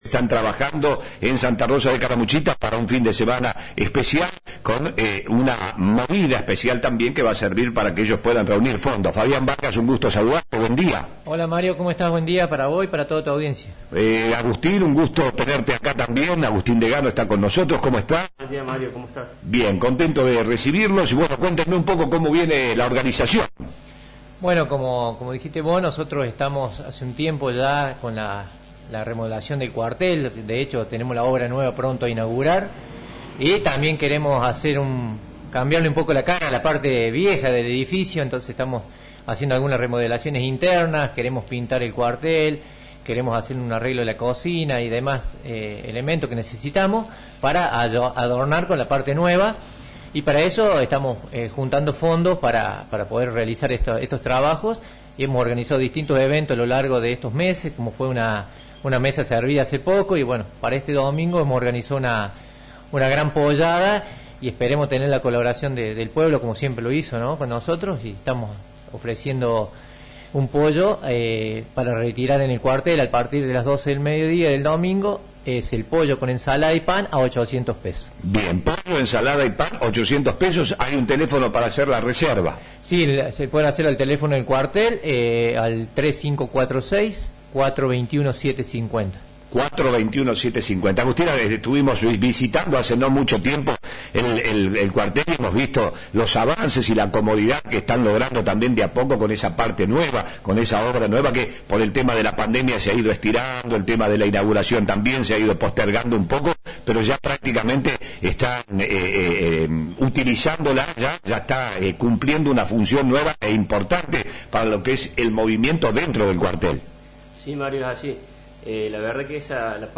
del Cuerpo de Bomberos Voluntarios de Santa Rosa visitaron nuestros estudios para invitar a la comunidad a participar de la campaña que llevan adelante para conseguir recursos económicos que permitirán seguir avanzando en las mejoras de infraestructura del cuartel. Este domingo organizan una «pollada».